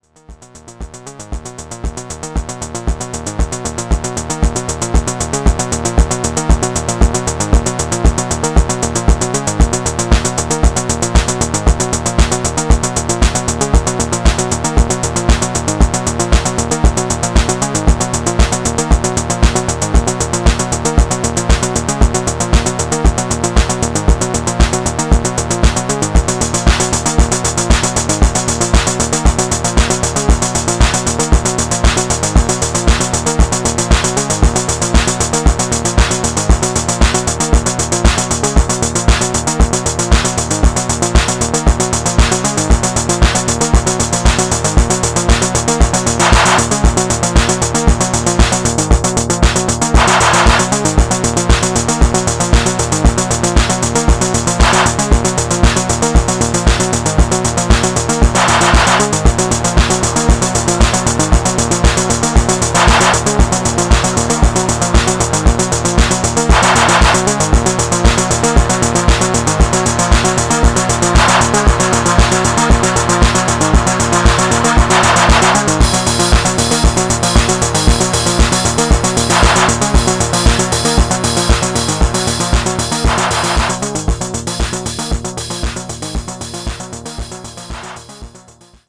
ポスト・パンク、シンセウェイヴ感のある切迫したアルペジオフレーズが熱気を高めてゆくB面